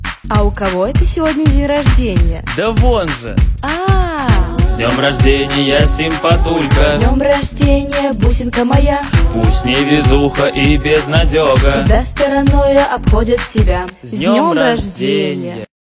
Архив Рингтонов, Прикольные Голосовые поздравления